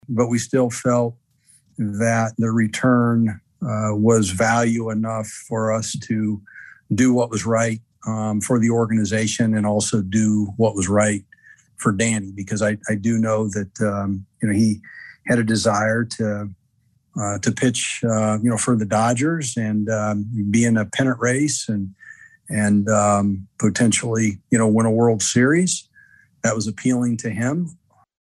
Royals GM Dayton Moore says this move is good for both the Royals and Duffy.